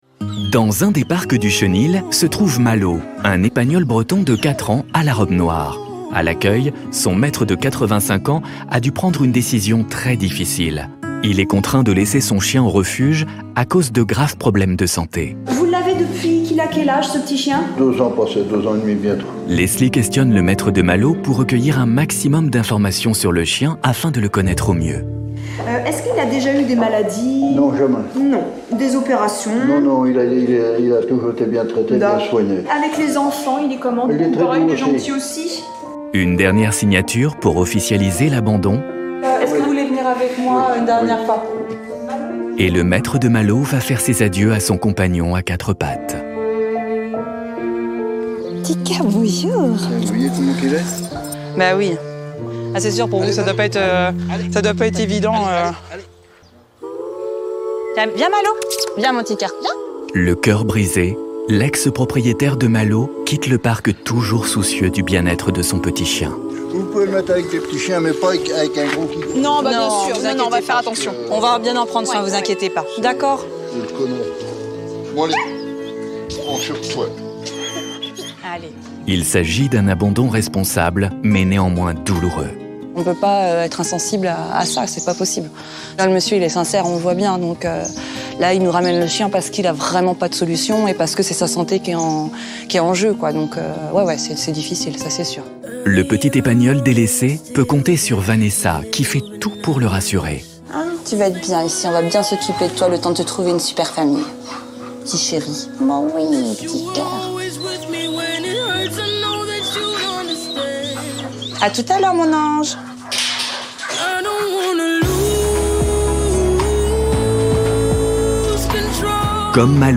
VOIX OFF émission
Sa voix a su traverser les époques sans jamais perdre de sa pertinence, de sa chaleur ni de son impact.